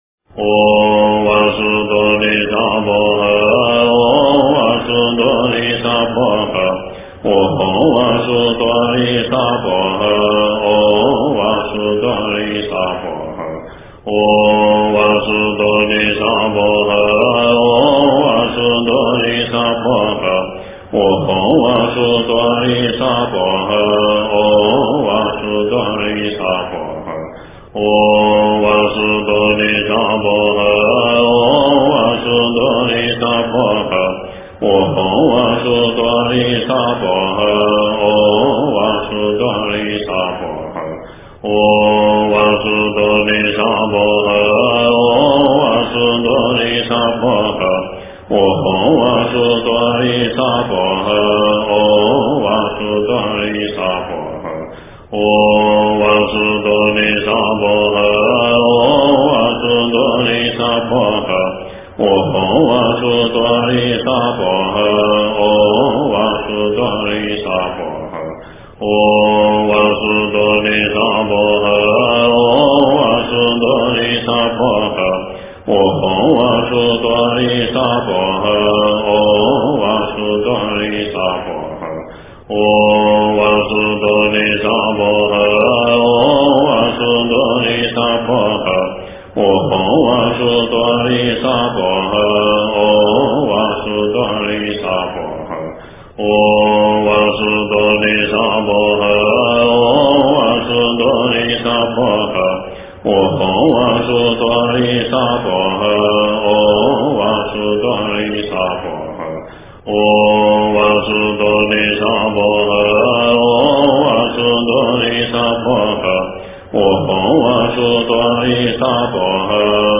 真言
佛音 真言 佛教音乐 返回列表 上一篇： 黄财神心咒--密咒真言 下一篇： 月藏经吉祥文--佚名 相关文章 大悲咒 大悲咒--圆满自在组...